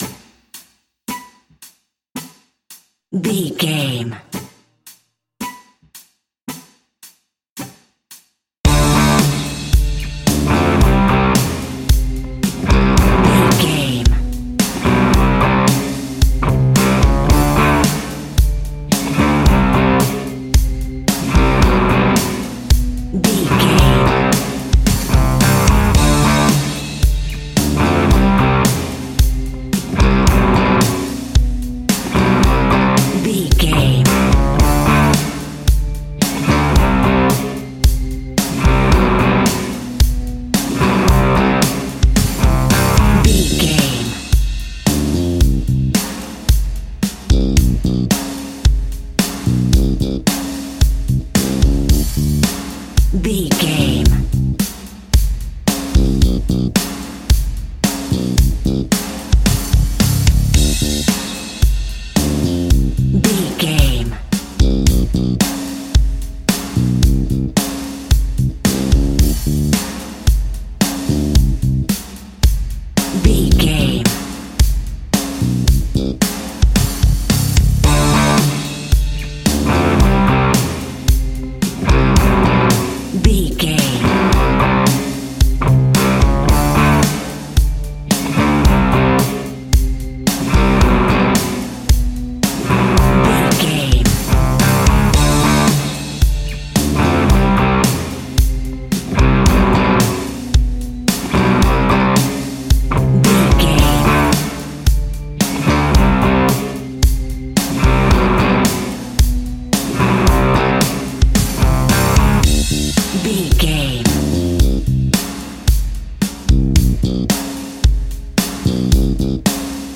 Ionian/Major
D
energetic
driving
aggressive
electric guitar
bass guitar
drums
hard rock
heavy metal
heavy drums
distorted guitars
hammond organ